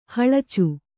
♪ haḷacu